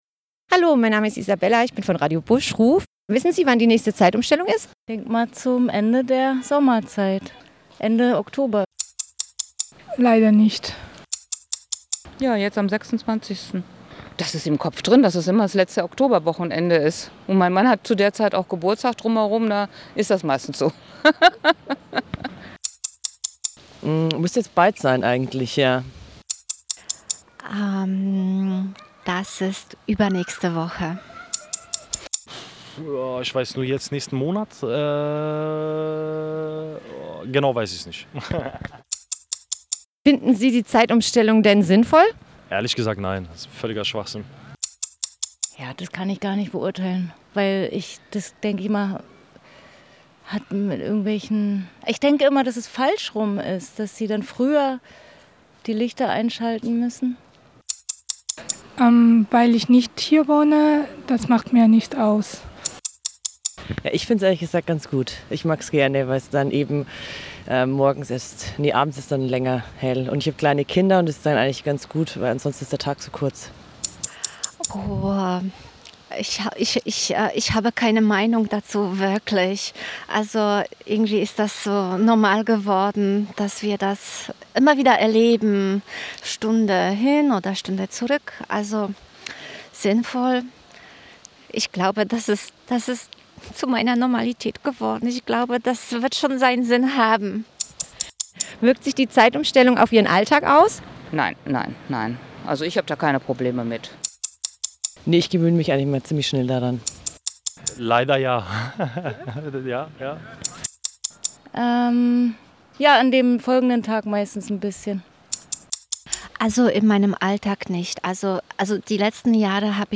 Strassenumfrage zur Zeitumstellung
Strassenumfrage-Zeitumstellung-3m08s.mp3